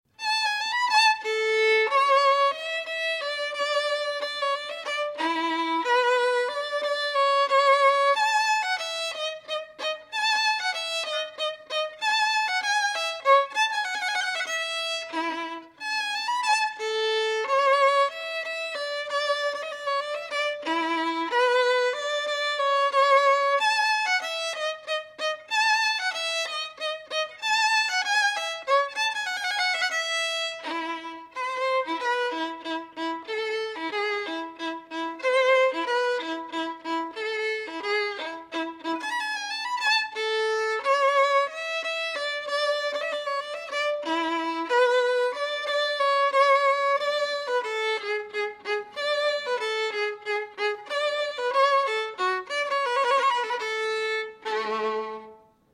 violin.ogg